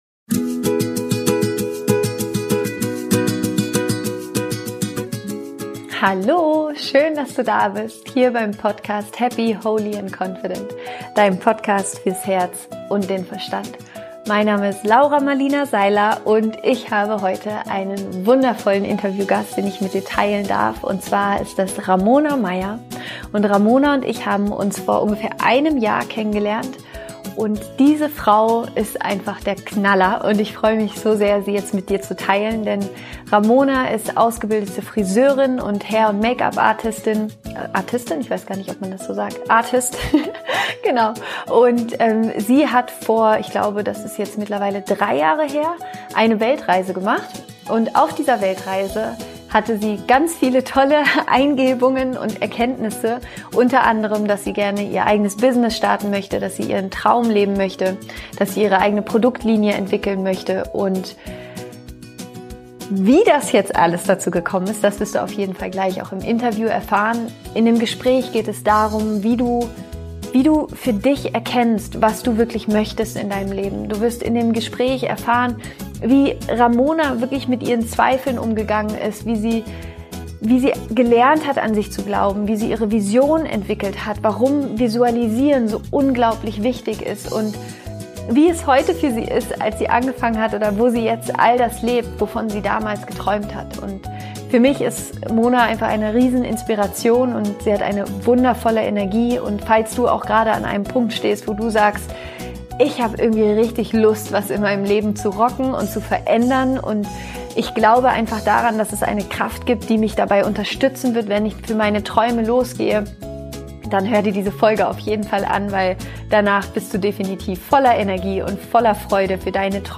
Wie du die Kraft der Visualisierung für dich einsetzen kannst - Interview